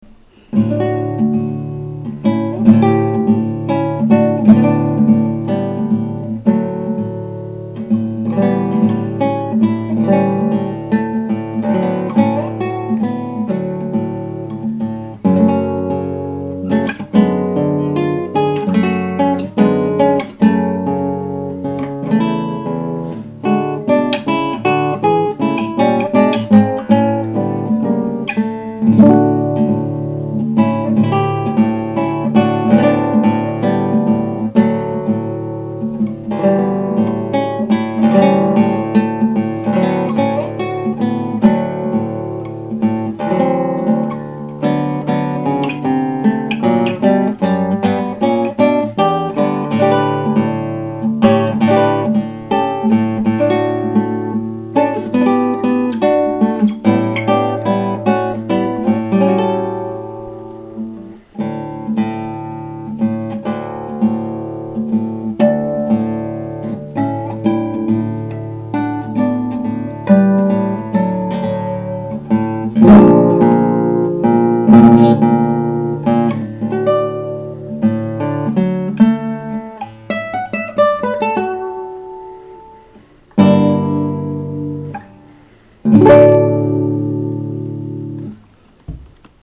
Atlanta Guitarist for Hire
One of Isaac Albéniz’s most beautiful piano works, transcribed for guitar by Michael Lorimer.